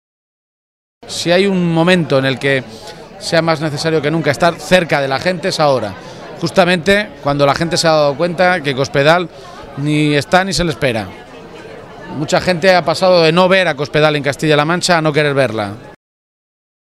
García-Page resaltó este compromiso en un encuentro comarcal celebrado en el municipio ciudadrealeño de La Solana, precisamente una comarca que si se cumplen las intenciones del Gobierno regional sufrirá las consecuencias de la privatización del hospital de referencia de Manzanares.